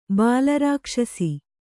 ♪ bāla rākṣasi